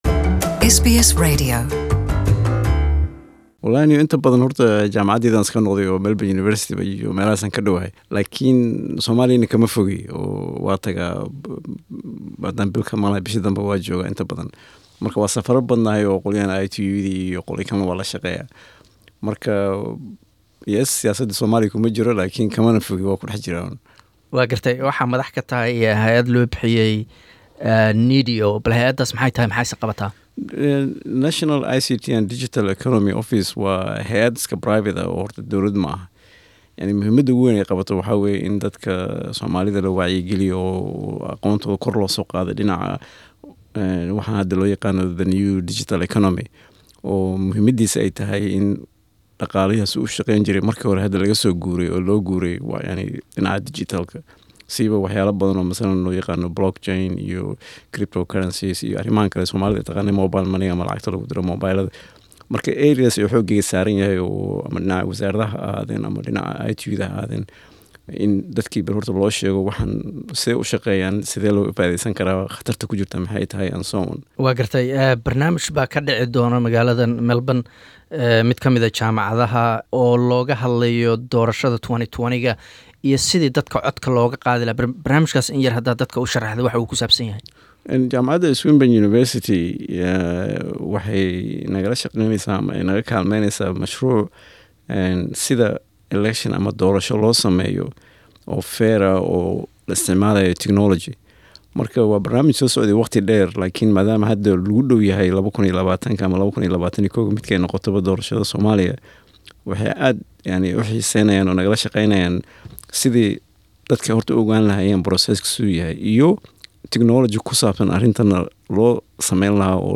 Interview: Former Somali telecommunication minister, Mohamed Ibrahim part 1